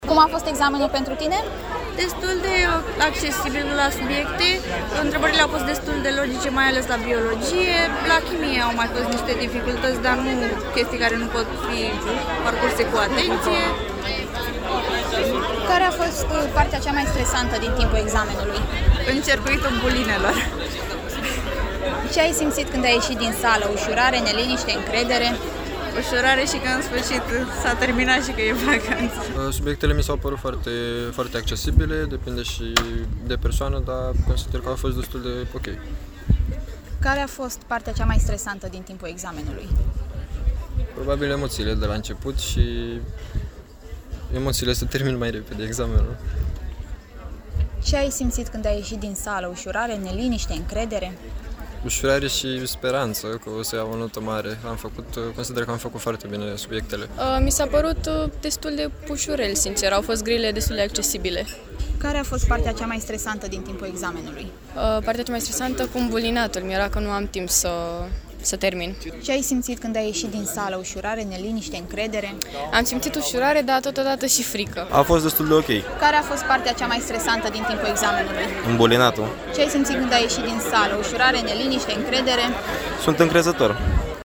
a stat de vorbă cu câțiva dintre ei: